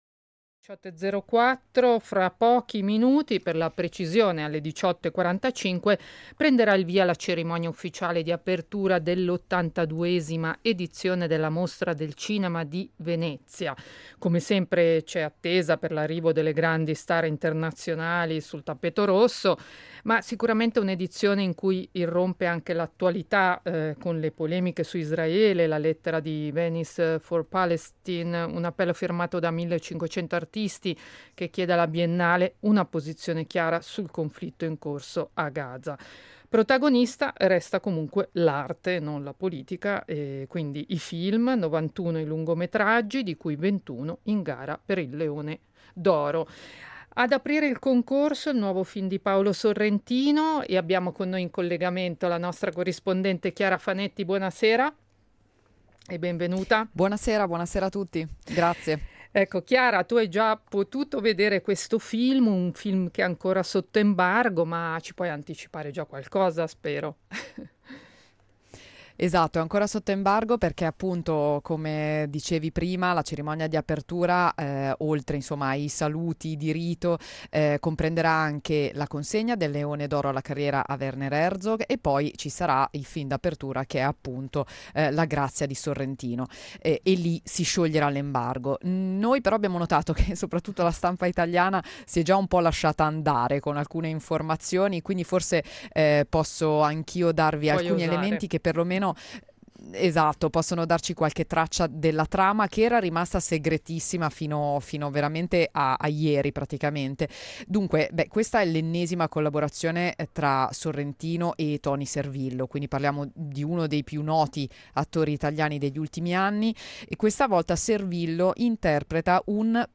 “Ho visto cose”, in diretta da Venezia